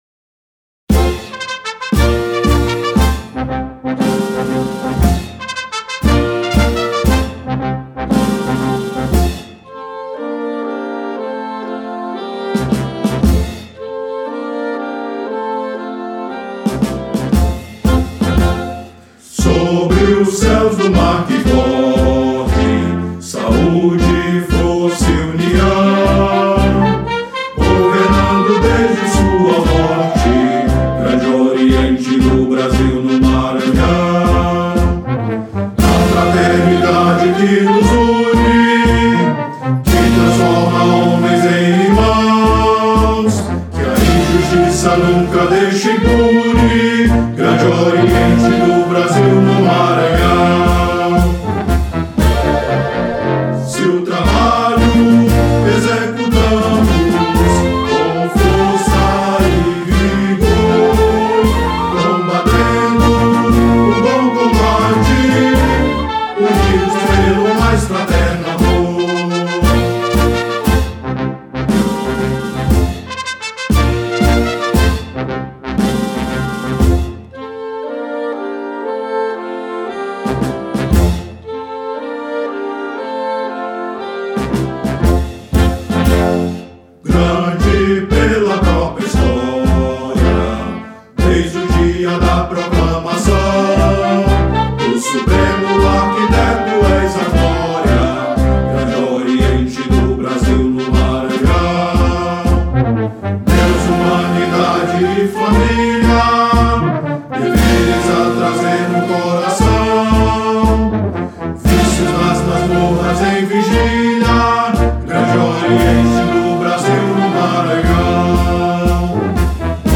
trompete
clarinete
flauta
sax alto
sax tenor
trompa
trombone
tuba
percussão